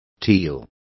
Also find out how trullo is pronounced correctly.